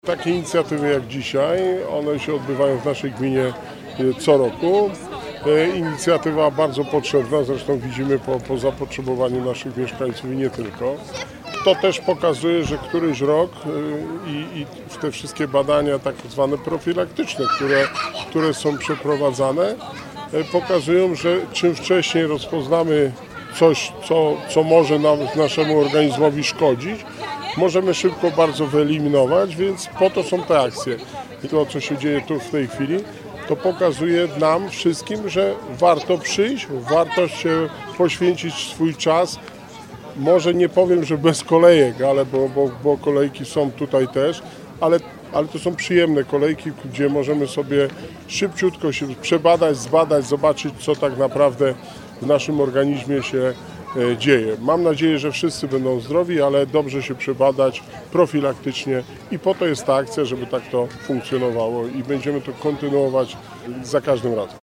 Zdrowa Aktywna Długołęka [relacja z wydarzenia]
– Widzimy, że jest zapotrzebowanie na takie wydarzenia – mówi Wojciech Błoński, wójt gminy Długołęka.